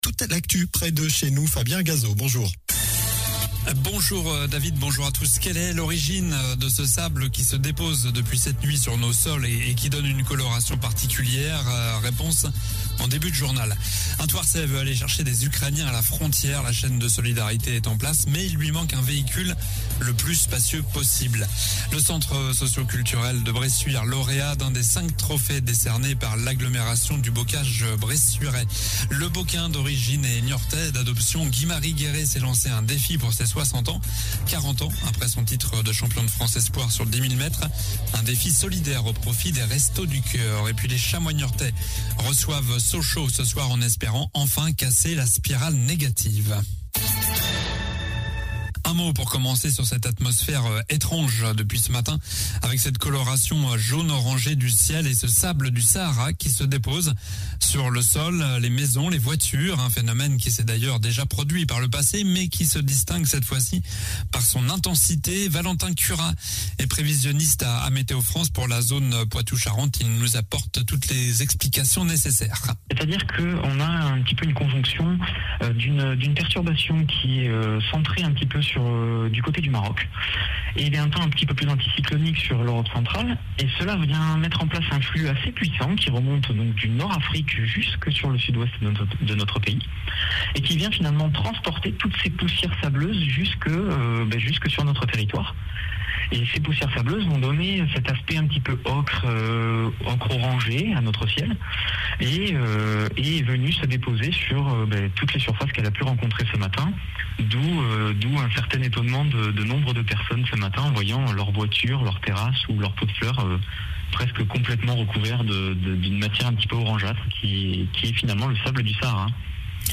Journal du mardi 15 mars (midi)